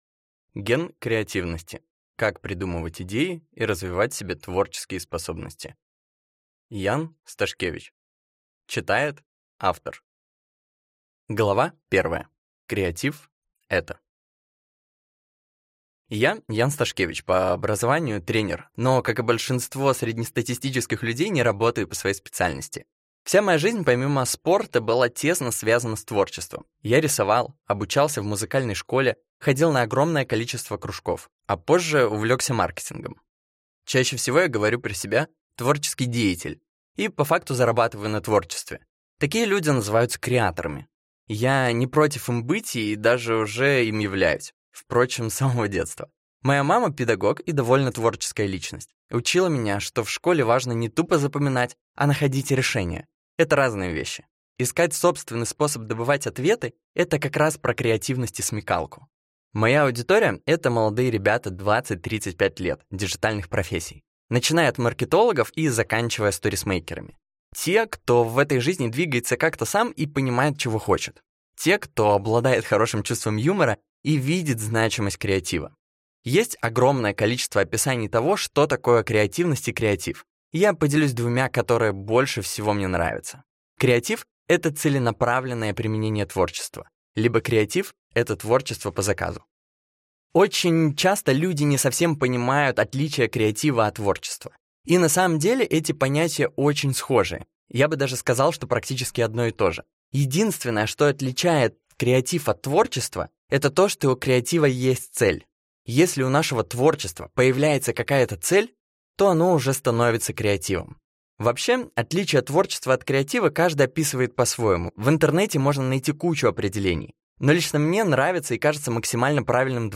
Аудиокнига Ген креативности. Как придумывать идеи и развивать в себе творческие способности | Библиотека аудиокниг